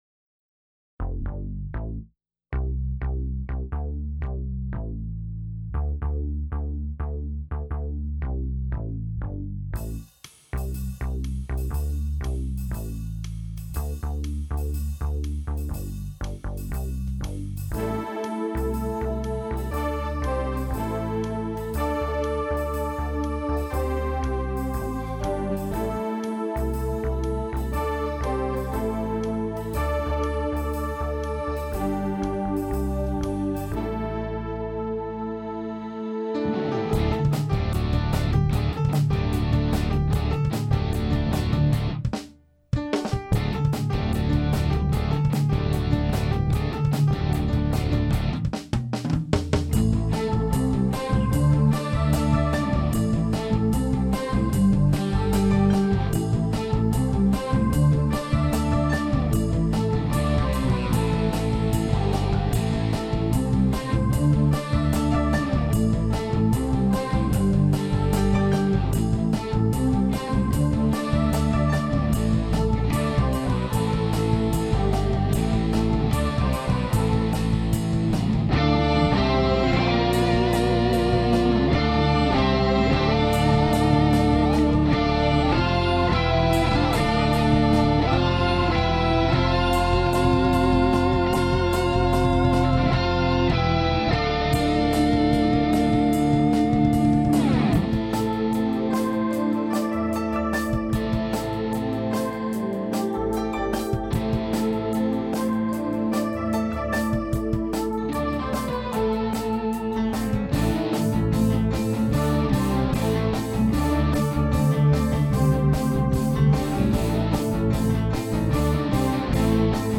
Rockin' Guitar